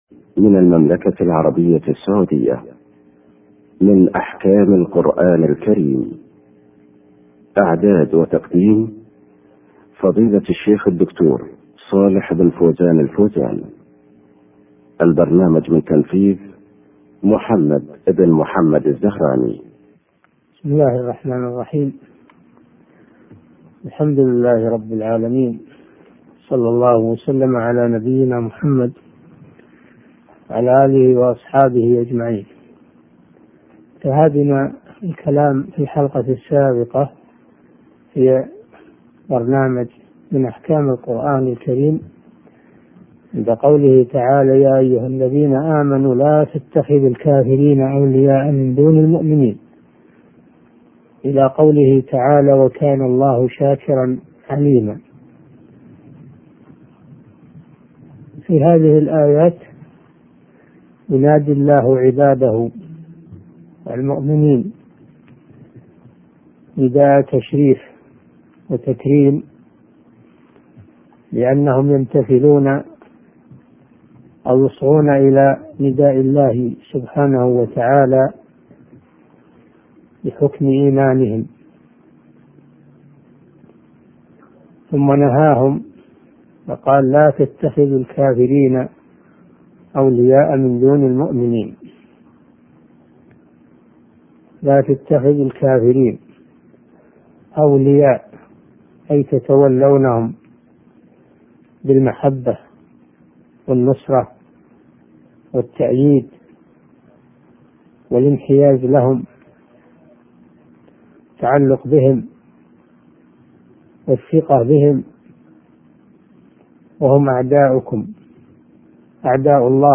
من أحكام القرآن الكريم الشيخ صالح بن فوزان الفوزان الدرس 6